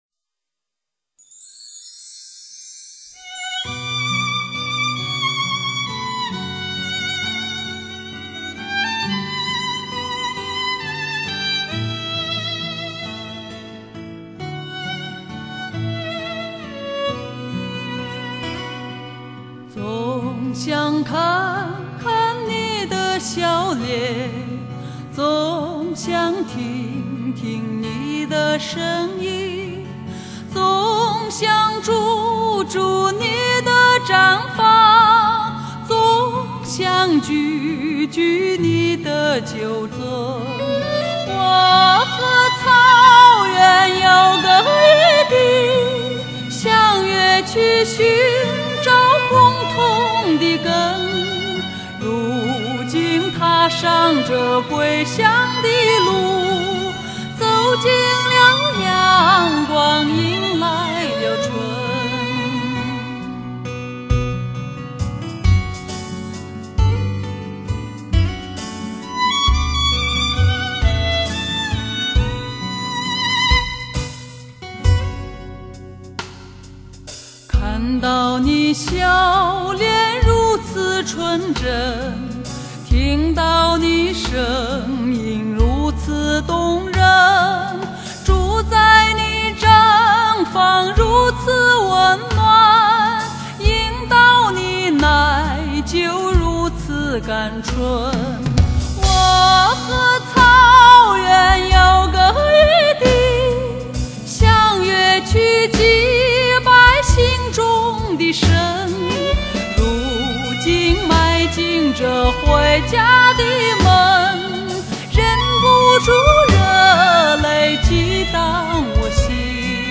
绝美的天籁音色，纯粹的发烧选曲，独特的韵味诠释；
马背上的歌声自由豪迈、动人心弦，是向苍天歌唱的民族宣言。
精选广为流传的草原经典民歌，